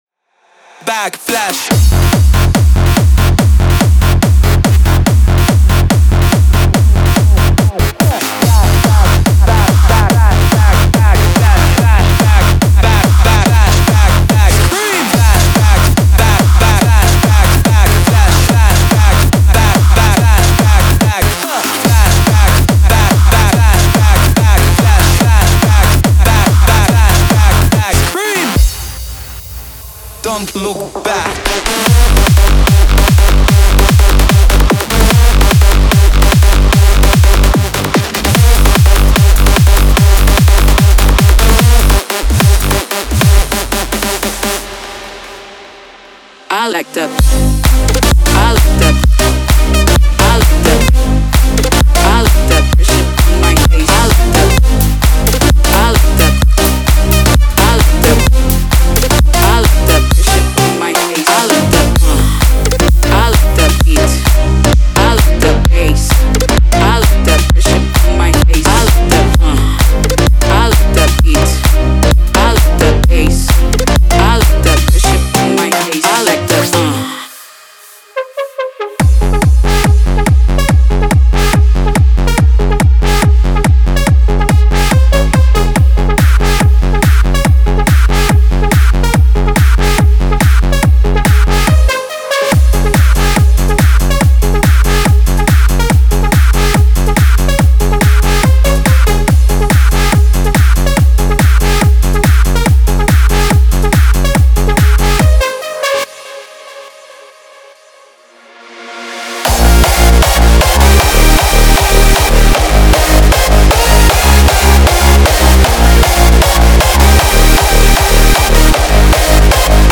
Eurodance Hardcore / Hardstyle
'Ultimate Dance Loops Vol 1 (baltic audio Edition)' by CESA SAMPLES includes 48 presets for Sylenth1 capturing the sound of classic Hands Up, Dance and Hard Dance. You'll find hand-crafted leads, basses, plucks and more.
The drums, various melodic samples and some FX used in the demo are not included in this product.